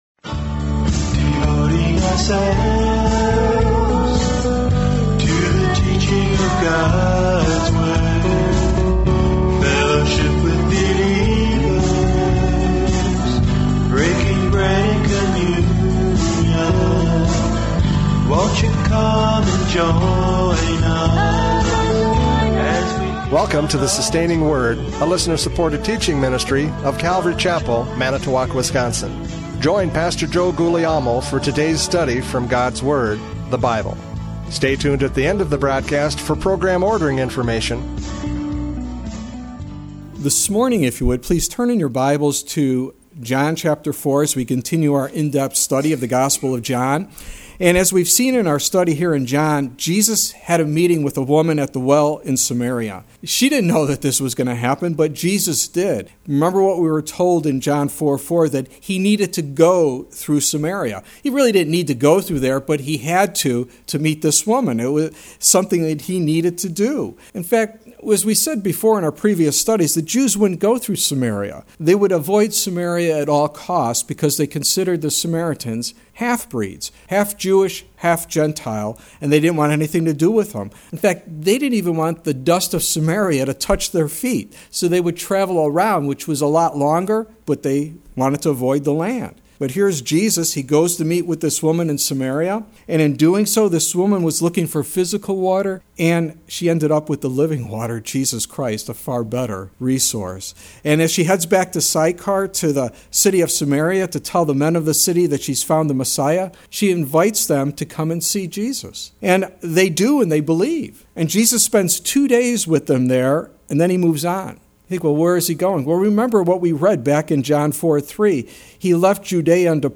John 4:43-54 Service Type: Radio Programs « John 4:27-42 Physical or Spiritual?